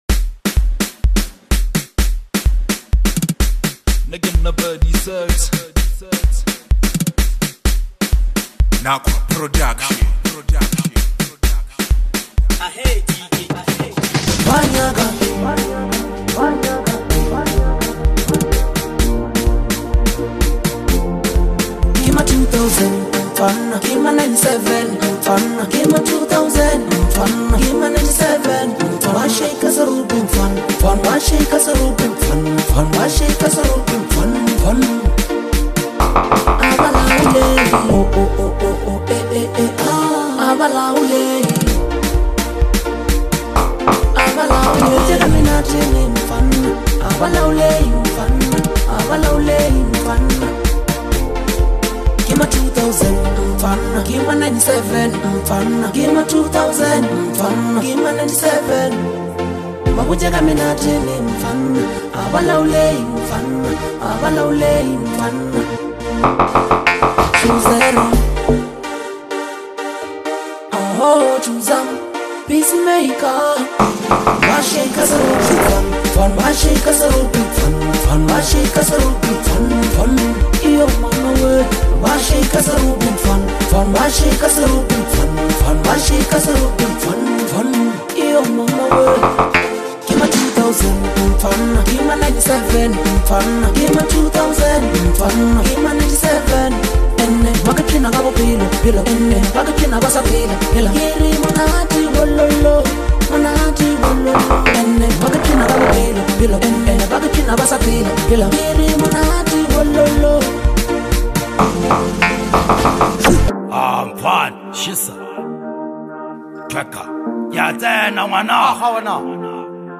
Home » Gqom » DJ Mix » Lekompo